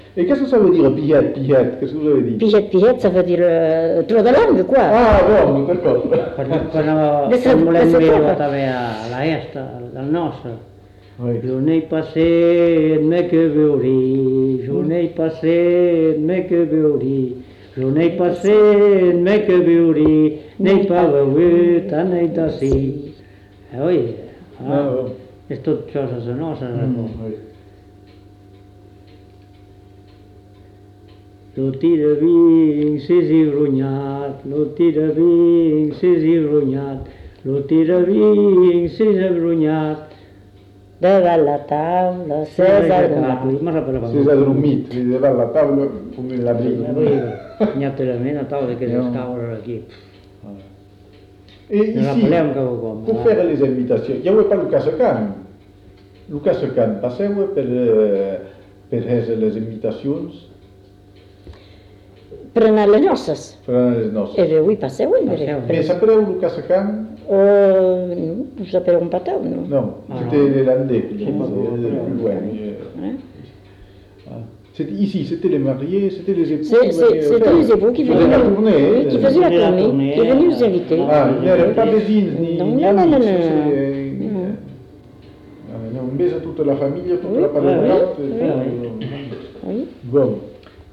Aire culturelle : Bazadais
Lieu : Cazalis
Genre : chant
Effectif : 1
Type de voix : voix d'homme
Production du son : chanté